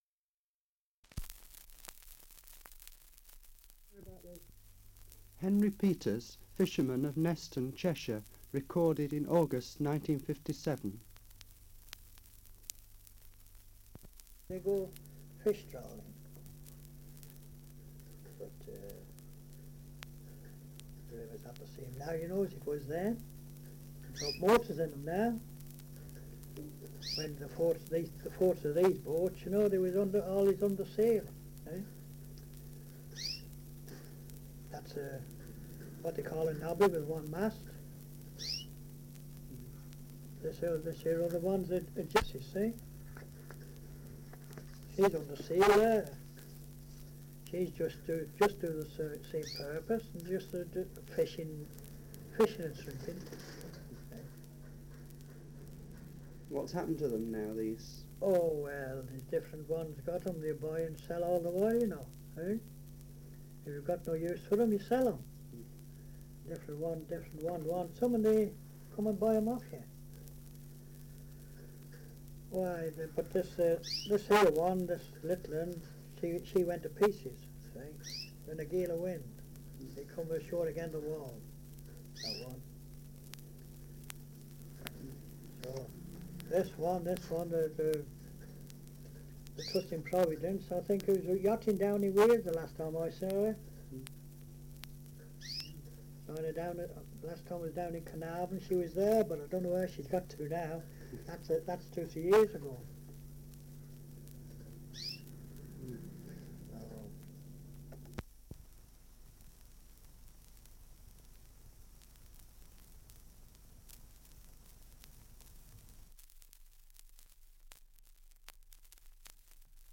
Dialect recording in Neston, Cheshire
78 r.p.m., cellulose nitrate on aluminium